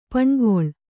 ♪ paṇgoḷ